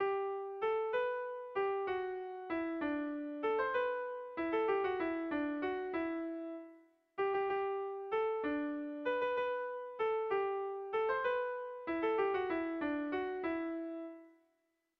Erlijiozkoa
Kopla handia
ABD